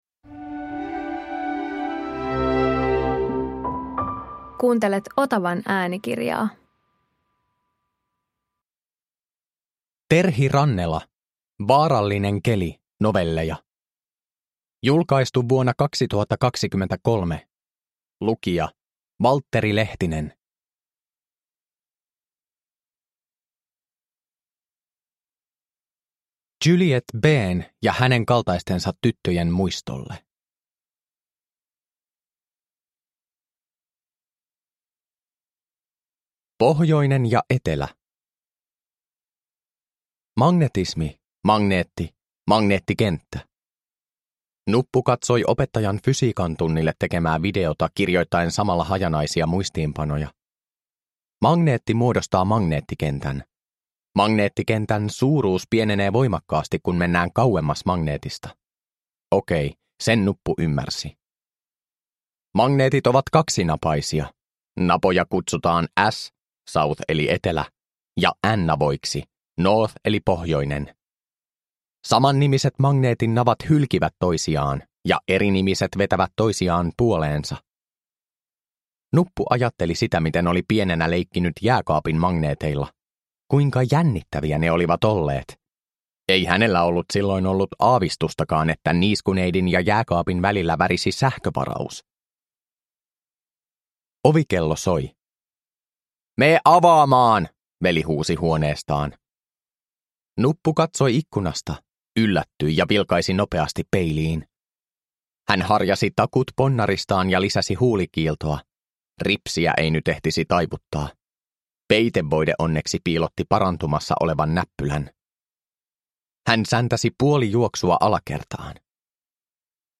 Vaarallinen keli – Ljudbok – Laddas ner